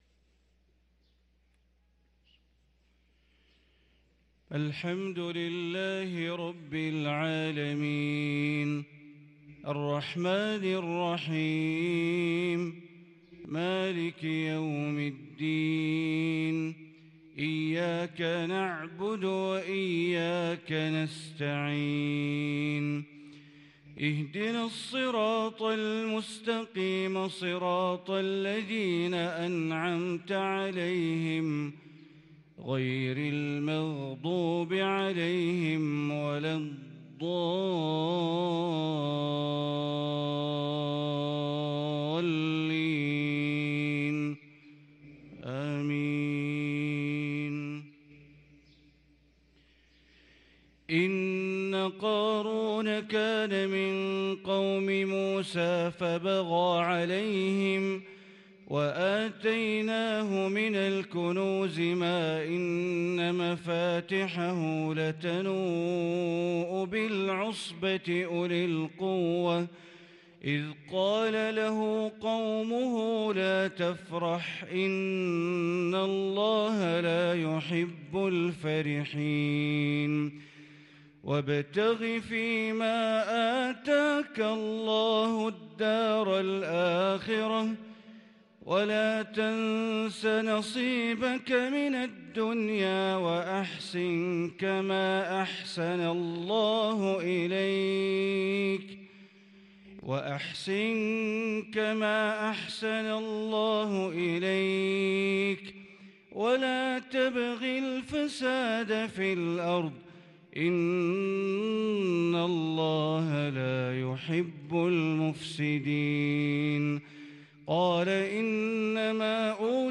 صلاة الفجر للقارئ بندر بليلة 12 جمادي الآخر 1444 هـ
تِلَاوَات الْحَرَمَيْن .